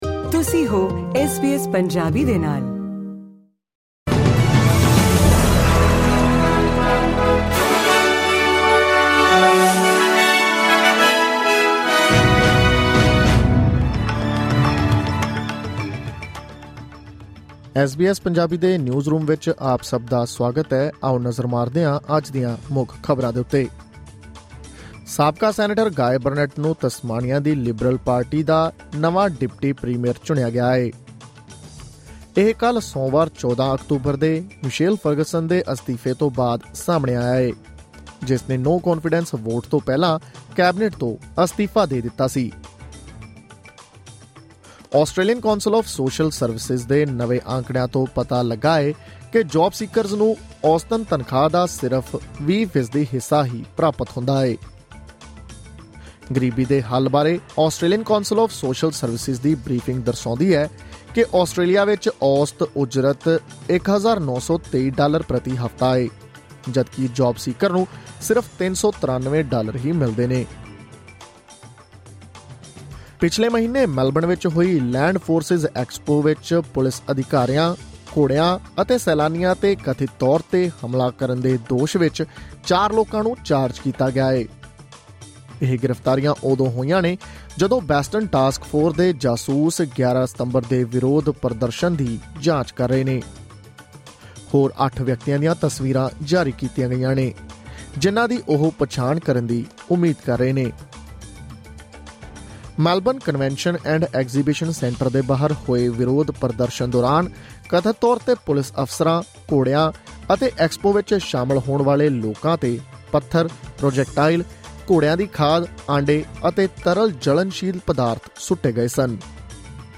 ਐਸ ਬੀ ਐਸ ਪੰਜਾਬੀ ਤੋਂ ਆਸਟ੍ਰੇਲੀਆ ਦੀਆਂ ਮੁੱਖ ਖ਼ਬਰਾਂ: 15 ਅਕਤੂਬਰ, 2024